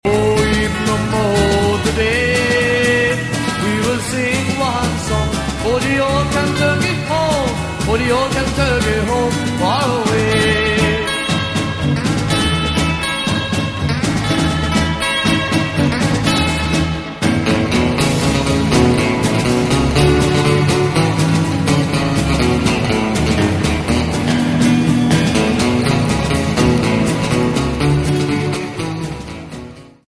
Category Country